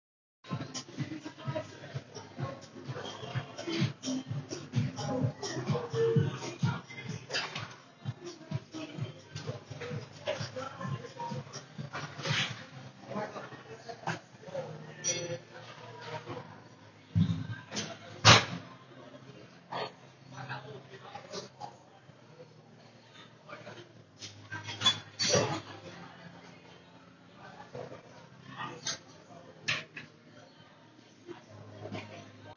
Field Recording
Sounds Heard: music playing, sounds of weights slamming, chattering, most men grunting
Gym.m4a